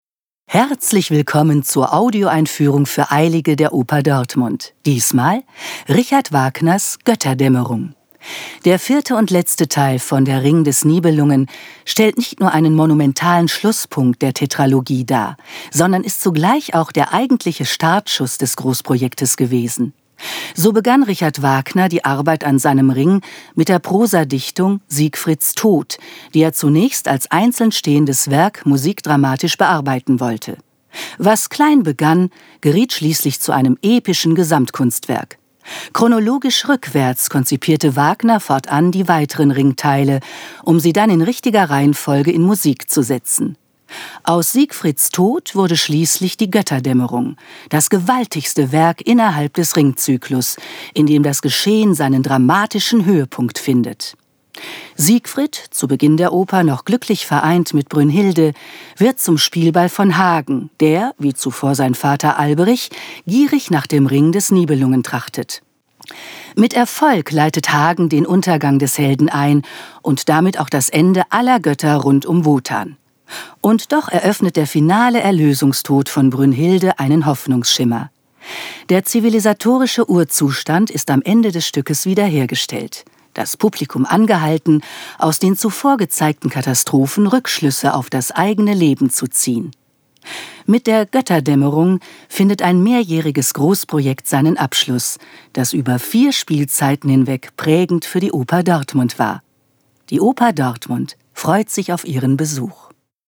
tdo_Audioeinfuehrung_Goetterdaemmerung.mp3